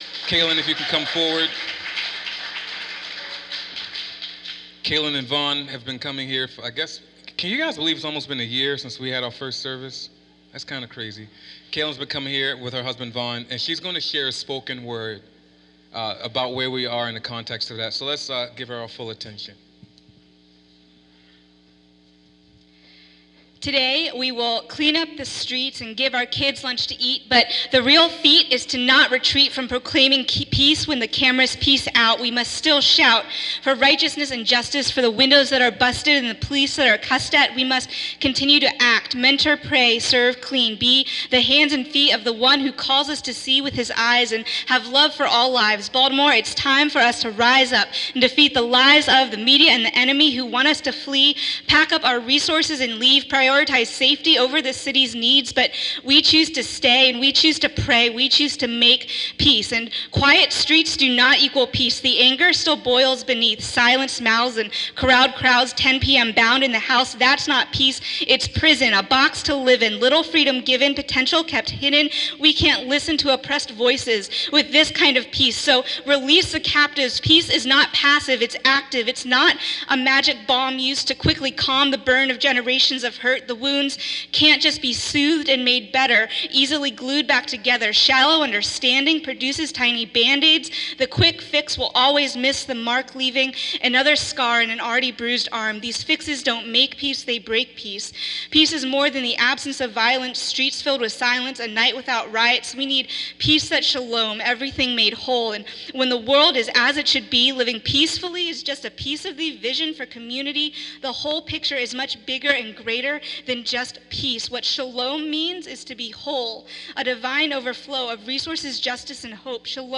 Here is a poem that was performed at worship this Sunday (5.3.15).